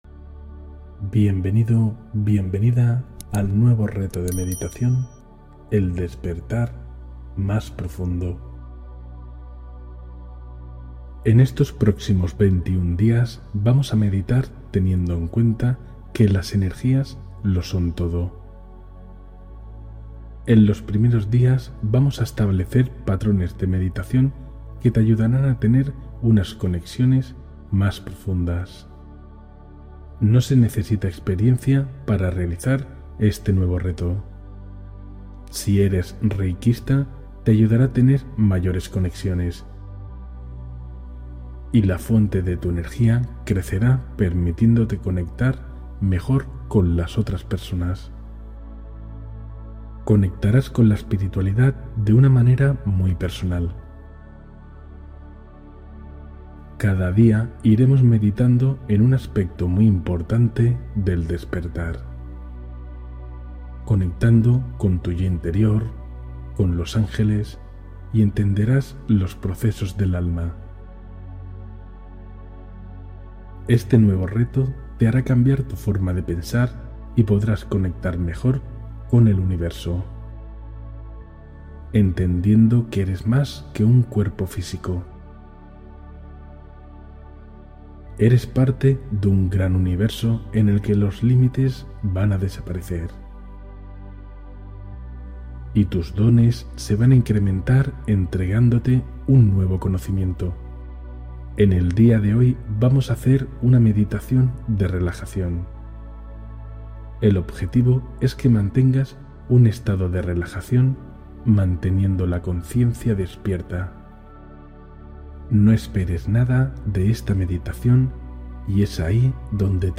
Meditación de Apertura del Tercer Ojo para Iniciar la Expansión Interior